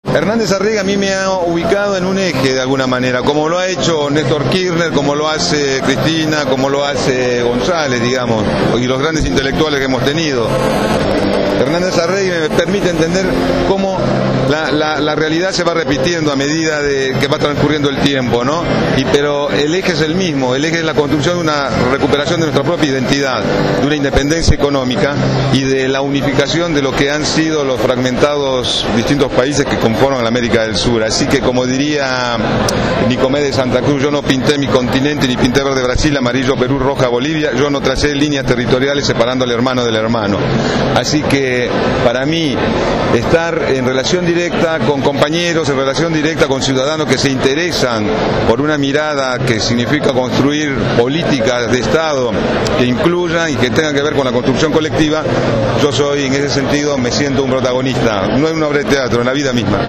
El actor Juan Palomino participó de la convocatoria para conmemorar el sexto aniversario del rechazo al ALCA